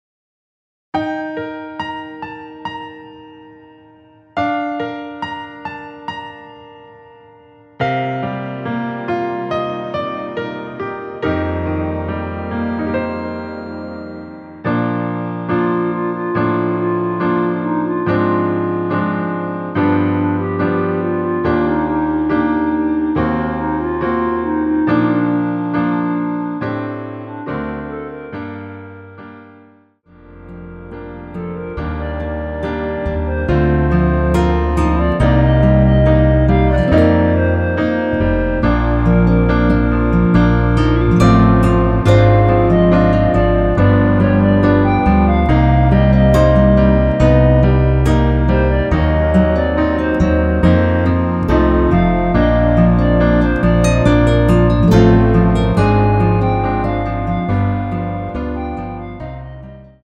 원키 멜로디 포함된 MR입니다.
Bb
앞부분30초, 뒷부분30초씩 편집해서 올려 드리고 있습니다.
중간에 음이 끈어지고 다시 나오는 이유는